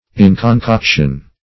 Search Result for " inconcoction" : The Collaborative International Dictionary of English v.0.48: Inconcoction \In`con*coc"tion\, n. The state of being undigested; unripeness; immaturity.